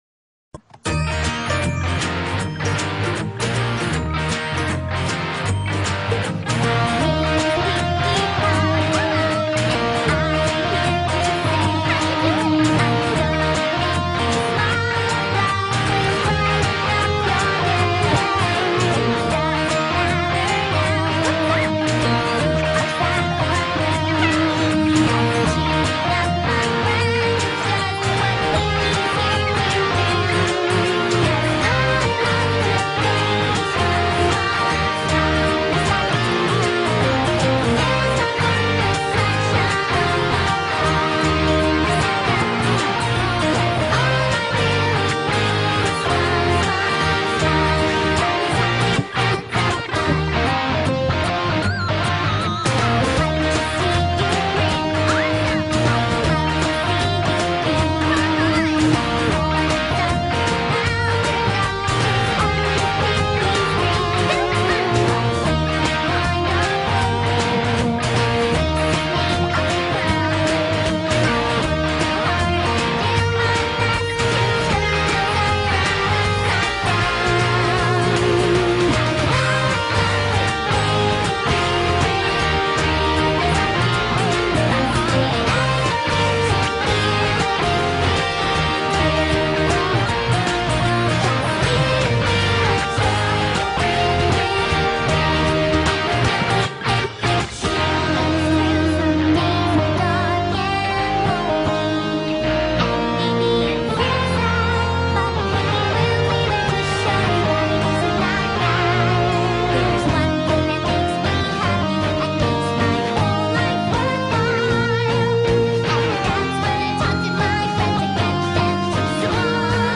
(Guitar and Bass Cover)